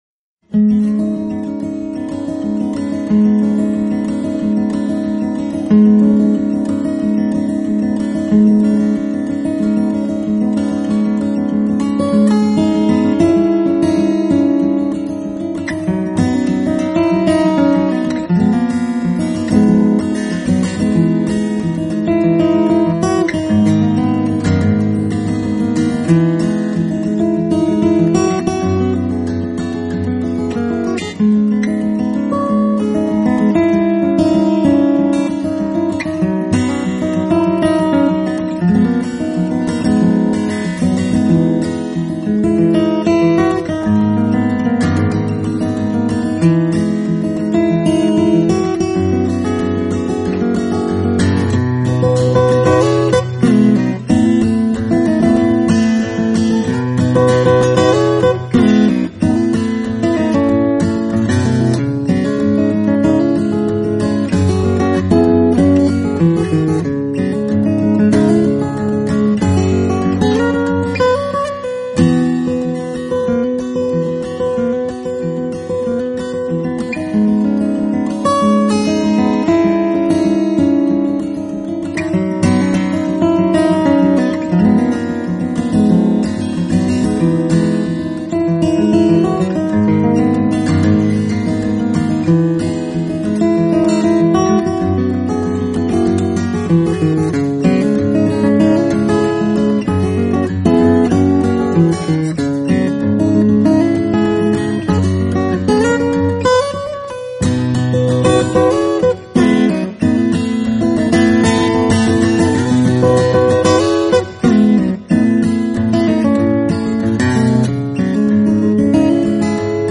“手指技法(finger style)”